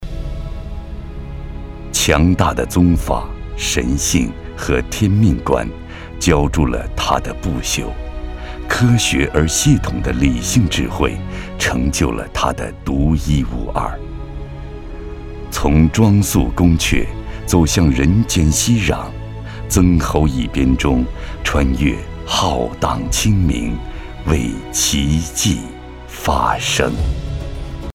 • 19专业男声7
专题解说-内敛深沉